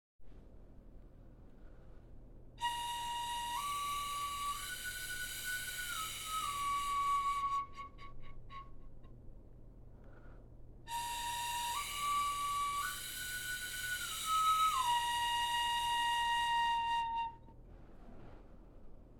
Bone Flute
bone_flute.mp3